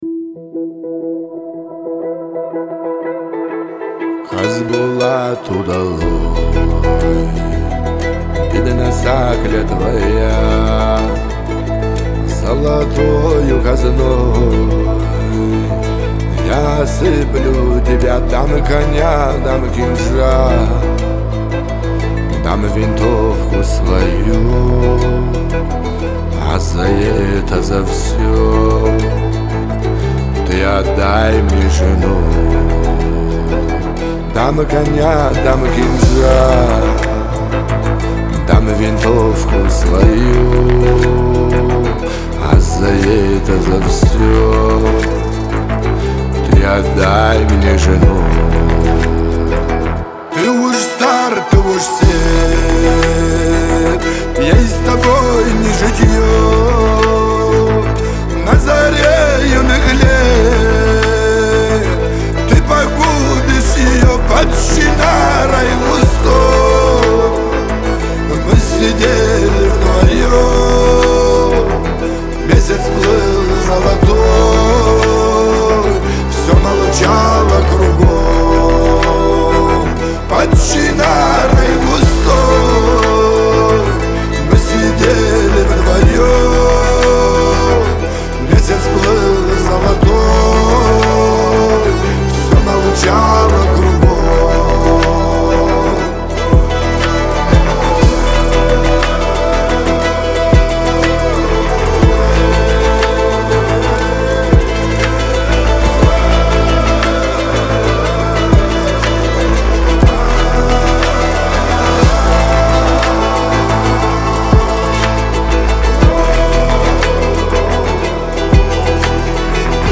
Адыгская музыка